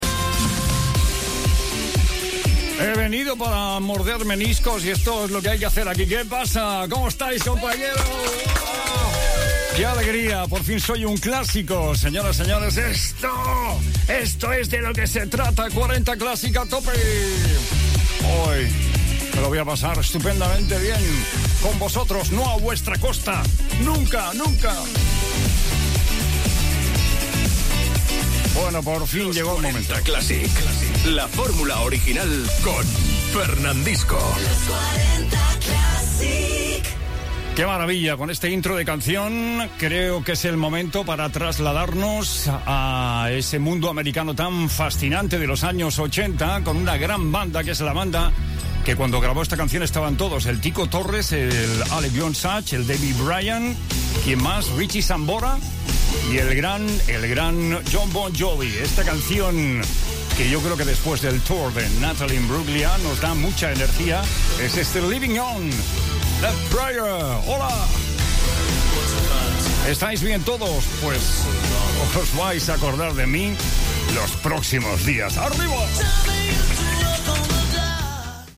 Primera presentació de Fernandisco a la cadena Los 40 Classic. Salutació, indicatiu de l'emissora i presentació d'un tema musical
Musical
FM